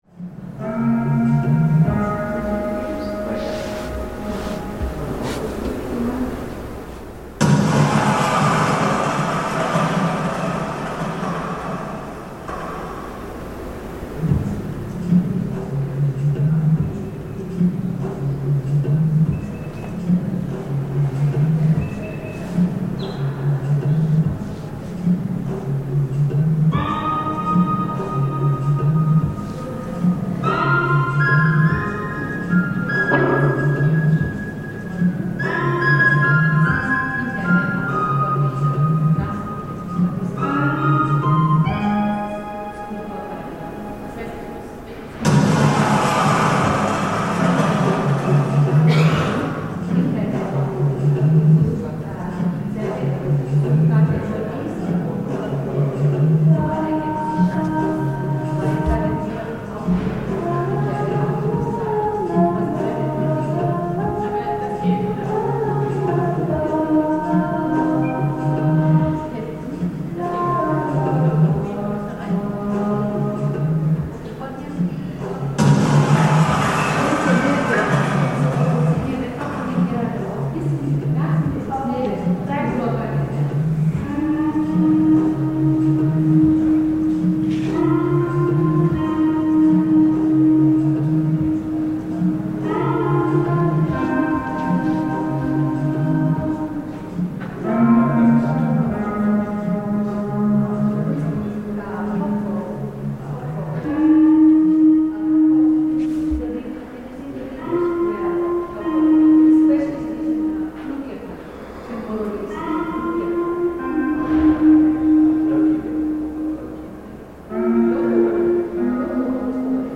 A video installation called "Ever Is Over All" by Pipilotti Rist (1997), at the Neue Nationalgalerie in Berlin - haunting music loops over and over, while in the video the artist walks along the street, then smashes car windows using a model of a large flower.